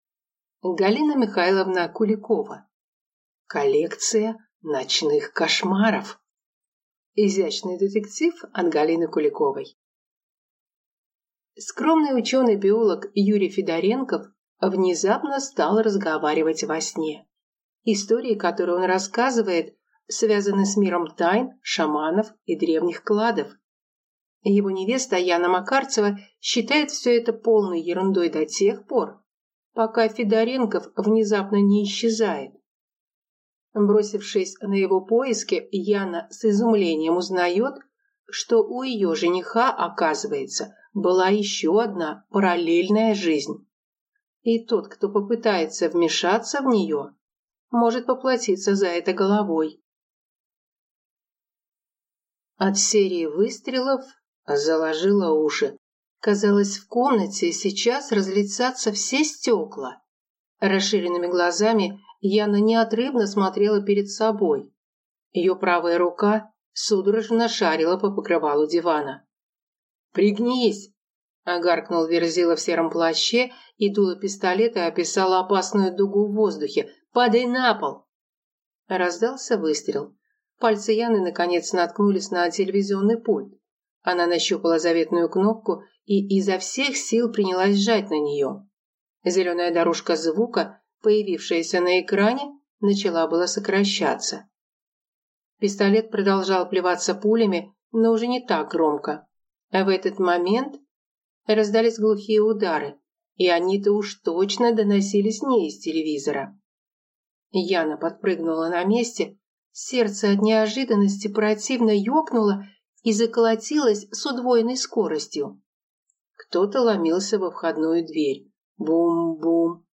Aудиокнига Коллекция ночных кошмаров